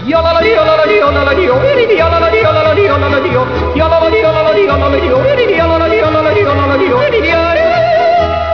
Yodel
YODEL.WAV